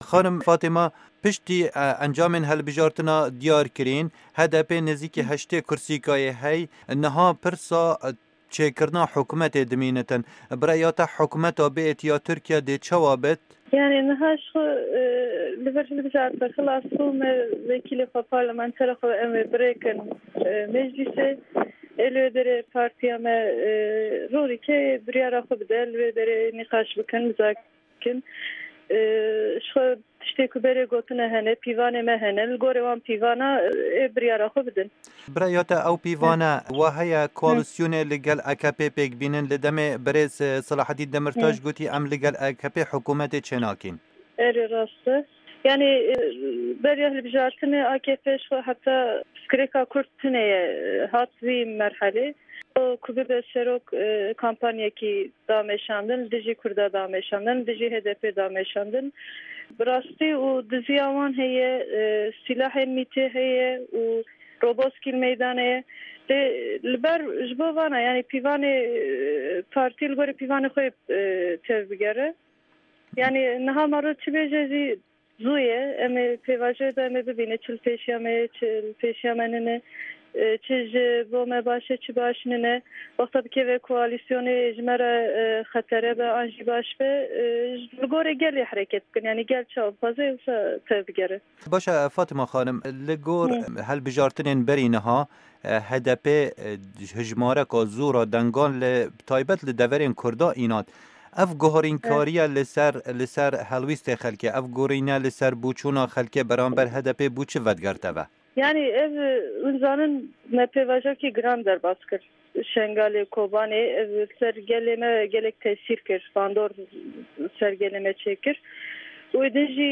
Di hevpeyvîneke Dengê Amerîka de digel hevseroka Sûra Amedê xanim Fatma Şik Barut dibêje, redkirin di sîyasetê da karekî nerast e, piştî nûnerên me biçin parlamenê ewê her tişt zelal bibe.
Hevpeyvin digel Fatma Şık Barut